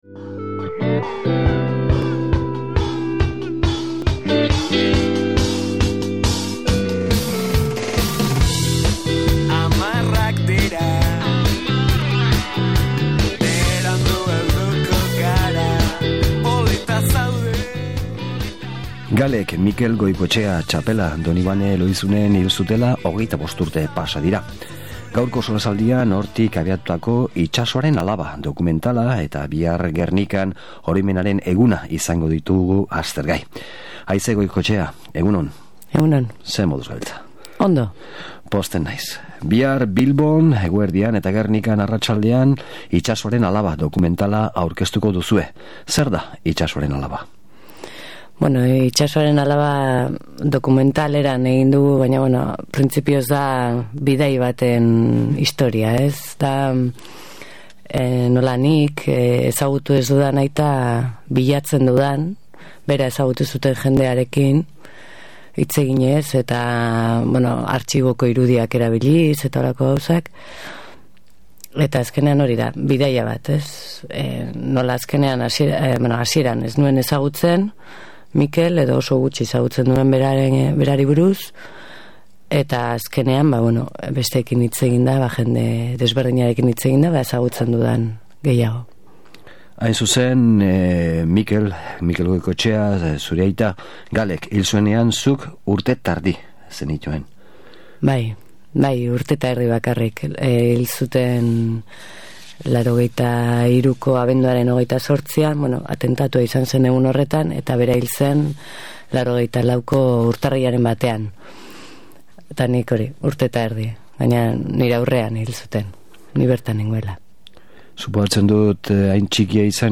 solasaldia
Gaurkoan bi gonbidatu izan ditugu Oroimenaren historikoa lantzeko asmoz.